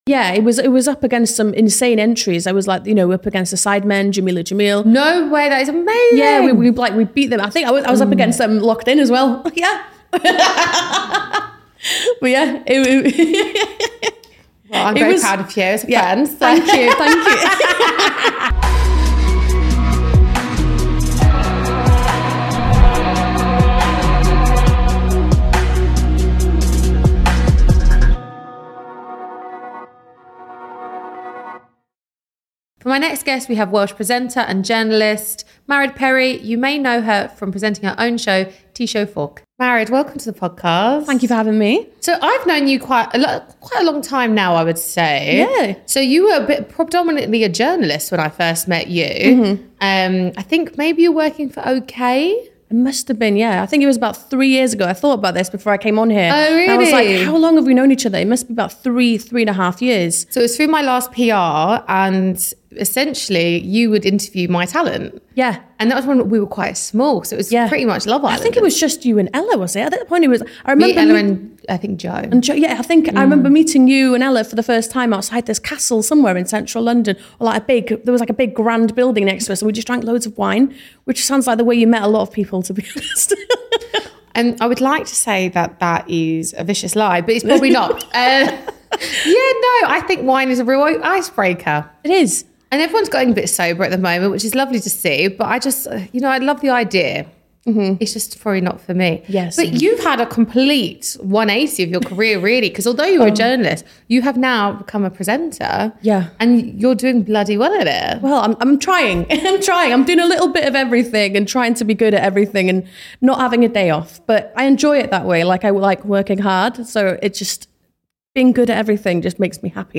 Managed is a podcast/ digital show interviewing industry leaders across media, business, sports and more who found success at a young age.